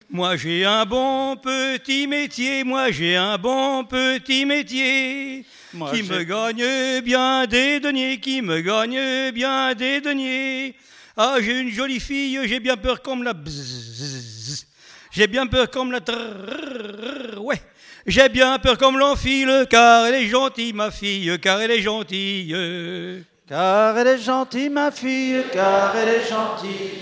Genre énumérative
Festival du chant traditionnel - 31 chanteurs des cantons de Vendée
Pièce musicale inédite